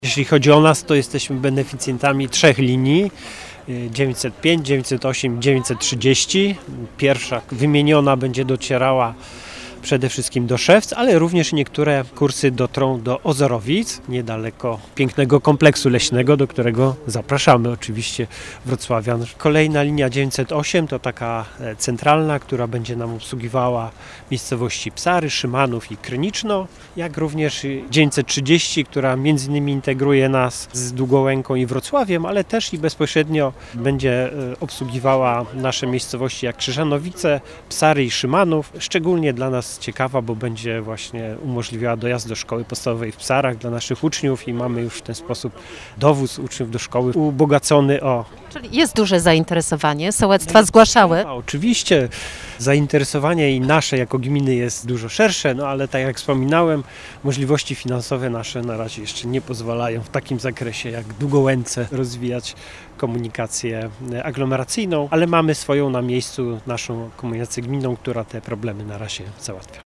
Częstsze połączenia w tygodniu i nowe kursy weekendowe zostaną uruchomione na liniach 905 (Kromera-Szewce/Ozorowice) oraz 908 (Dworzec Nadodrze-Szymanów/Kryniczno). Jakub Bronowicki, wójt gminy Wisznia Mała mówi o zapotrzebowaniu i korzyściach z takiej siatki połączeń.